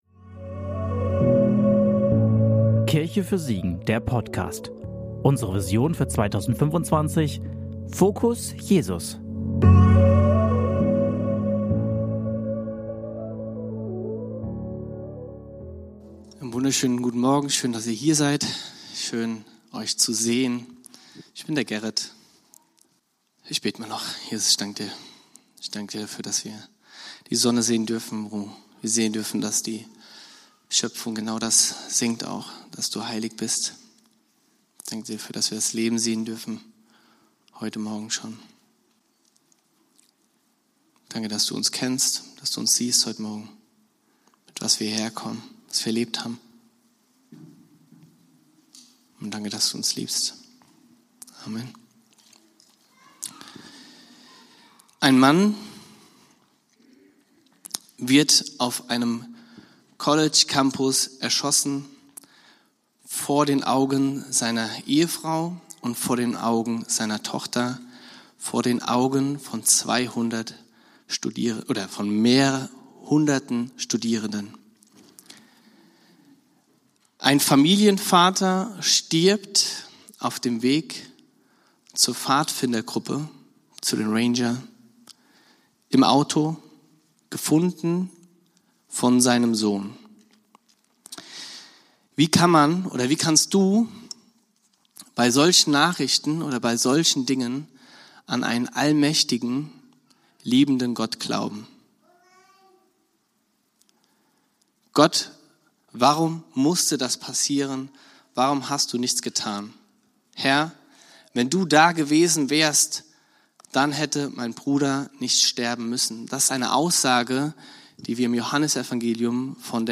Predigt vom 14.09.2025 in der Kirche für Siegen